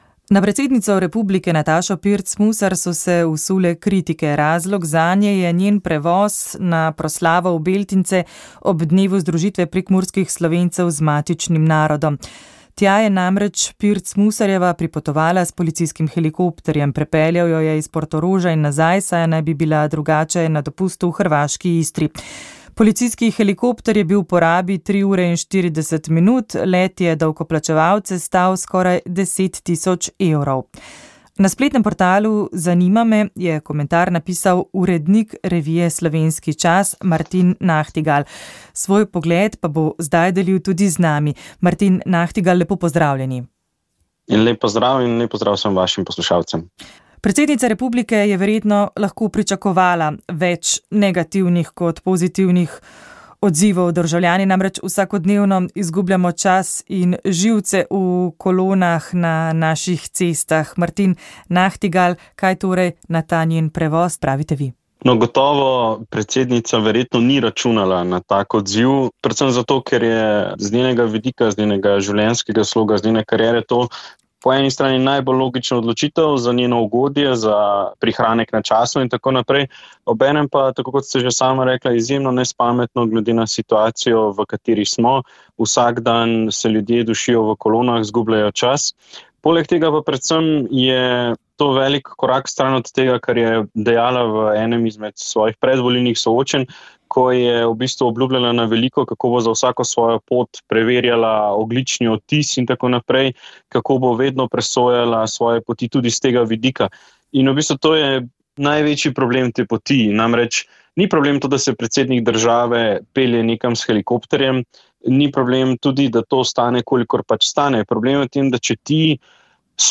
Za komentar smo poklicali dolgoletnega slovenskega diplomata in poznavalca mednarodnih odnosov Toneta Kajzerja.
pogovor